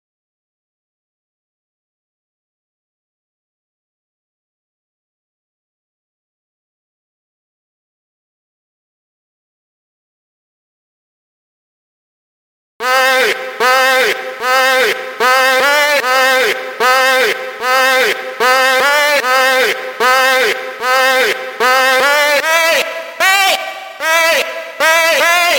PE声乐咏叹调事情 150bpm
描述：这个开头大空，因为它在循环的第二部分播放。
Tag: 150 bpm Electronic Loops Vocal Loops 4.31 MB wav Key : C